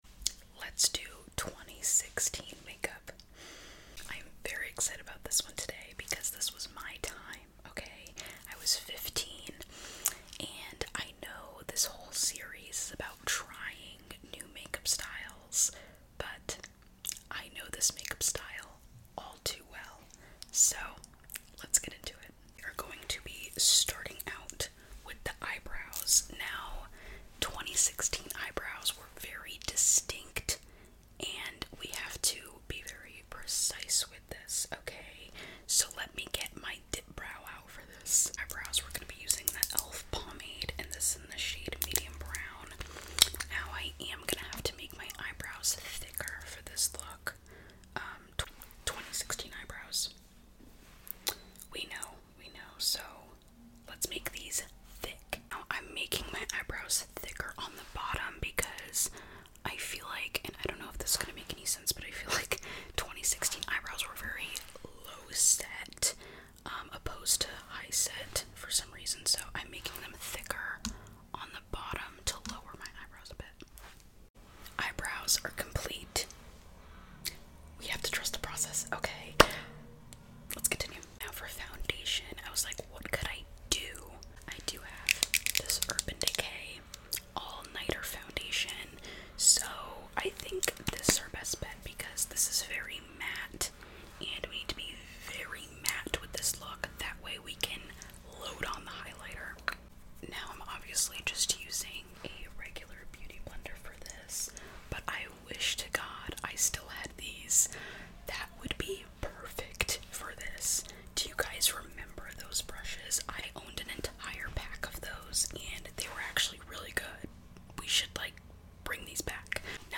trying 2016 makeup asmr💄😋 this sound effects free download